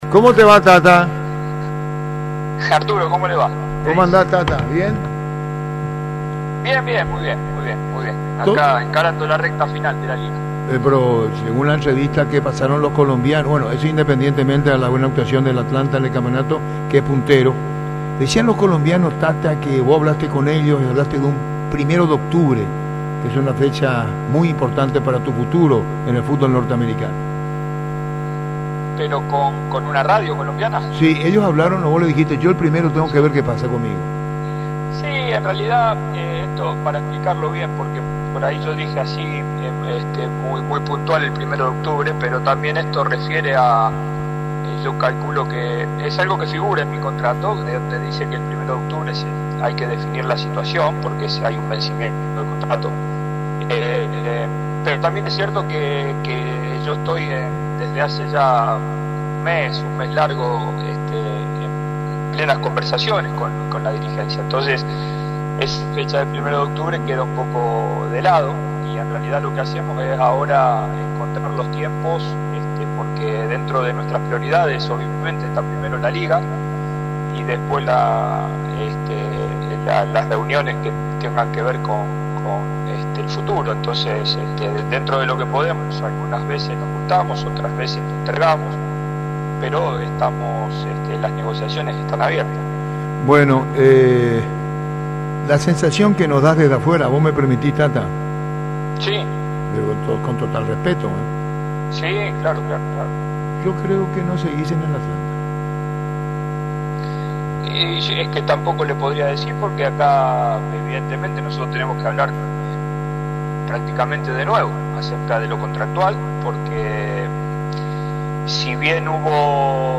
Gerardo Martino habló en exclusiva con Fútbol a lo Grande sobre su conversación con Robert Harrison acerca de un eventual regreso a la selección de Paraguay y contó su futuro inmediato como entrenador.